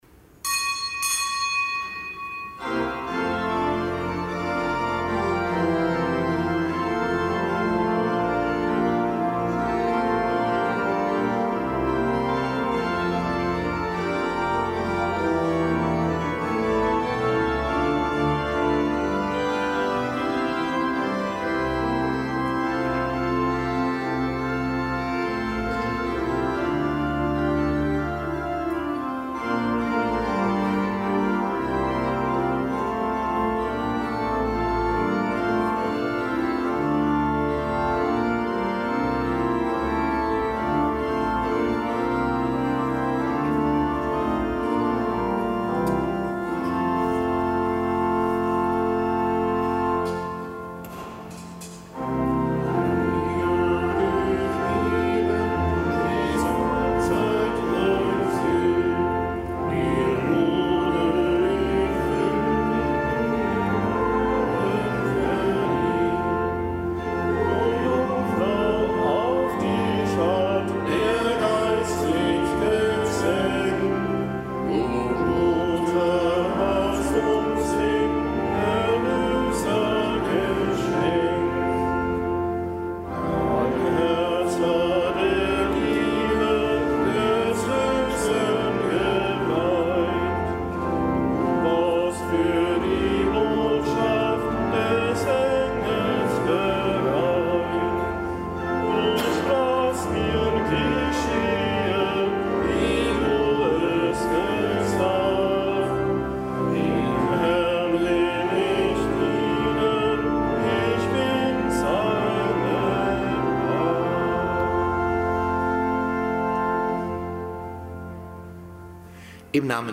Kapitelsmesse aus dem Kölner Dom am Samstag der dreizehnten Woche im Jahreskreis. Zelebrant: Weihbischof Dominikus Schwaderlapp.